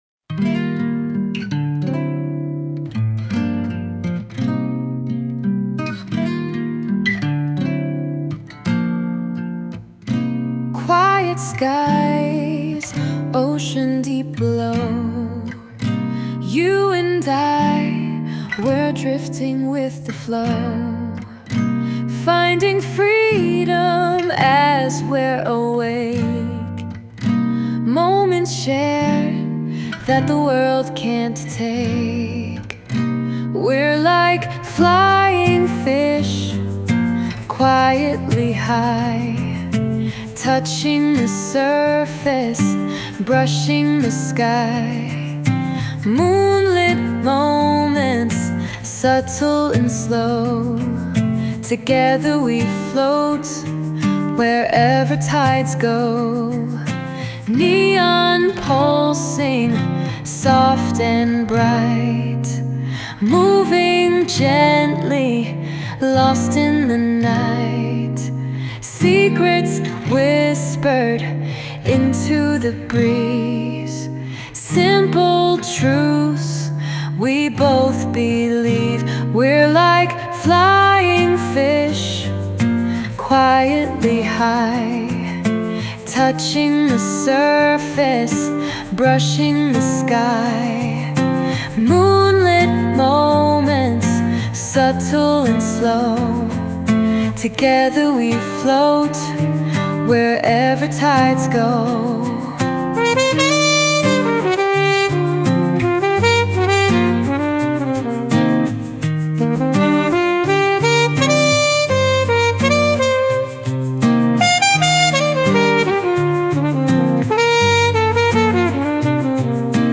Genre: Children’s Music / Kids Tunes